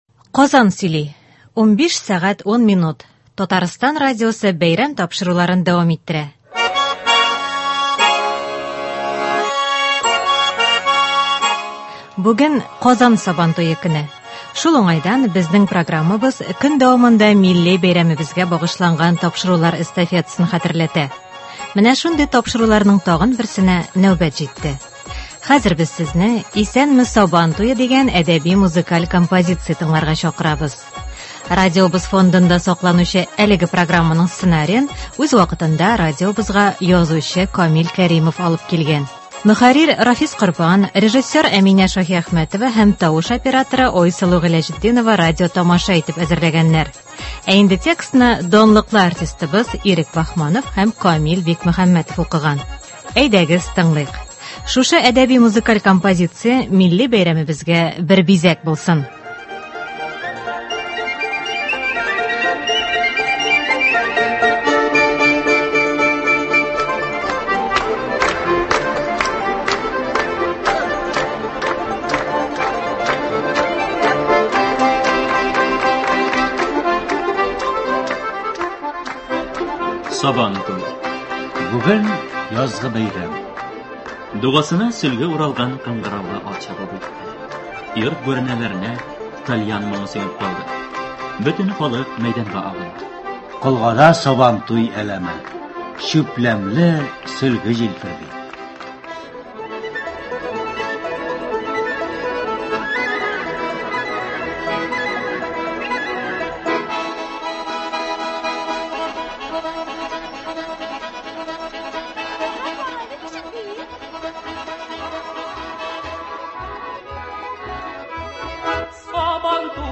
Шигъри-музыкаль композиция.